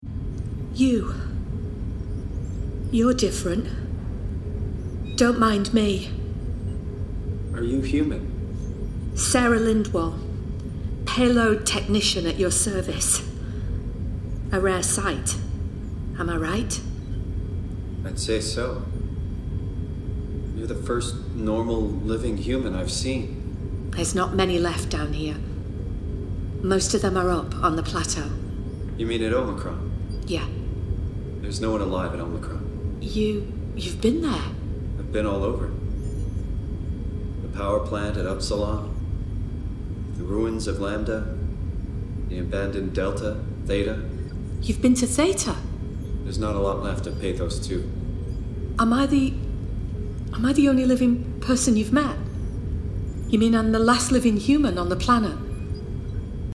40/50's Northern,